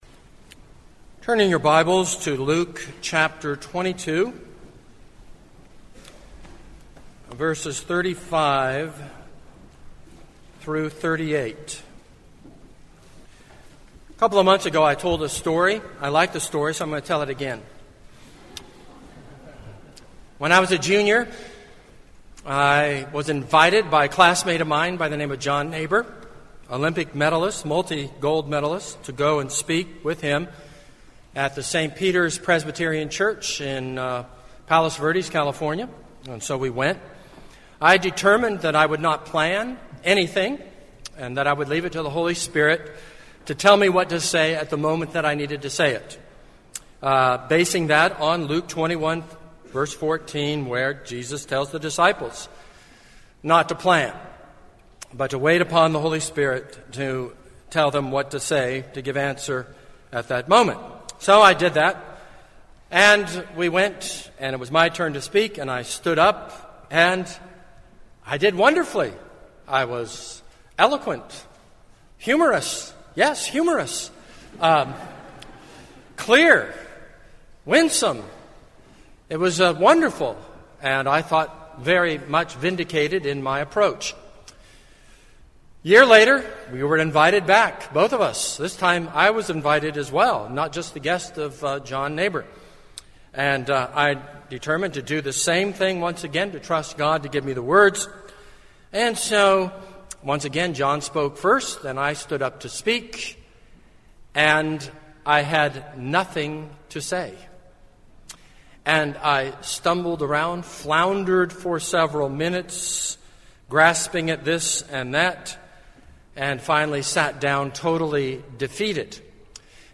This is a sermon on Luke 22:35-38.